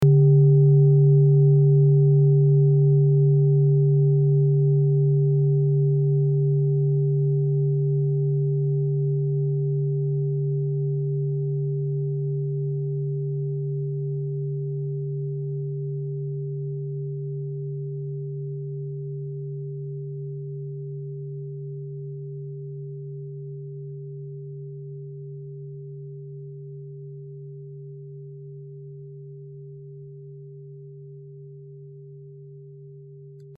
Klangschalen-Typ: Bengalen und Tibet
Klangschale 5 im Set 4
Durchmesser = 21,9cm
(Aufgenommen mit dem Filzklöppel/Gummischlegel)
klangschale-set-4-5.mp3